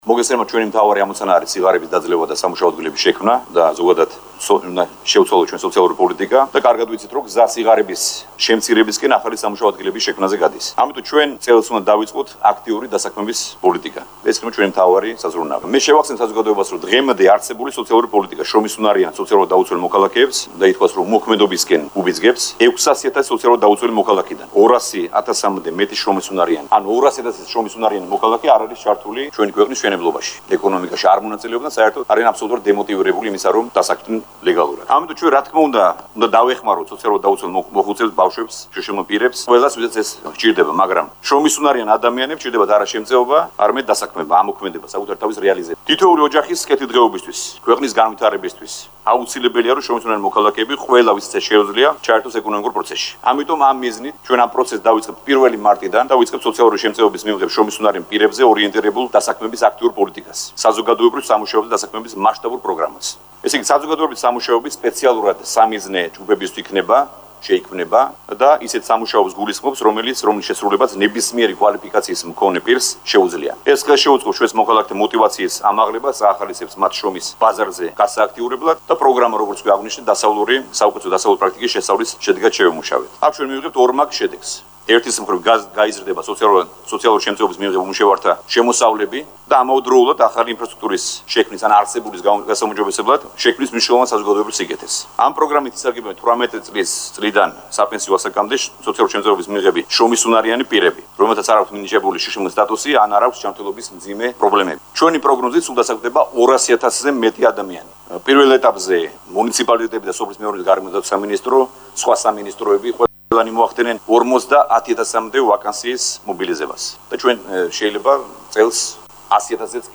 ამის შესახებ პრემიერ-მინისტრმა ირაკლი ღარიბაშვილმა მთავრობის სხდომაზე განაცხადა.
ირაკლი ღარიბაშვილის ხმა